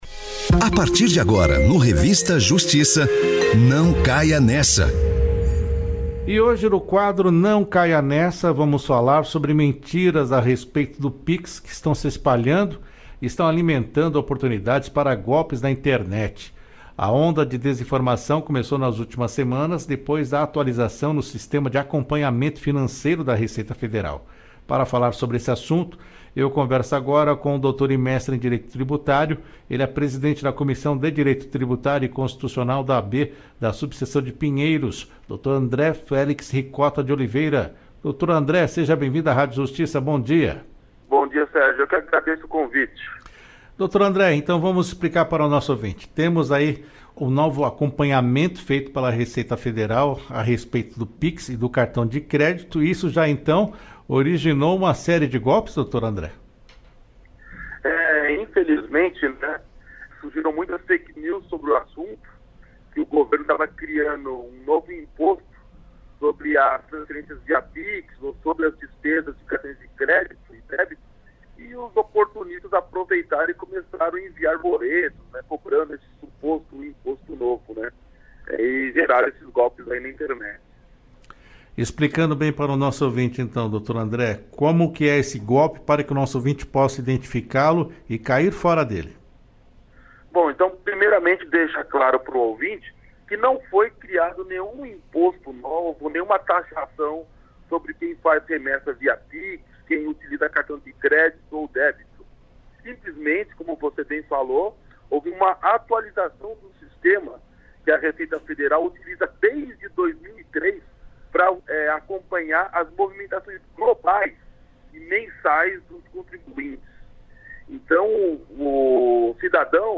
Entrevista a Rádio Justiça sobre a onda de falsas informações sobre o PIX